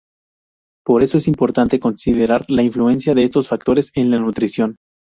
con‧si‧de‧rar
/konsideˈɾaɾ/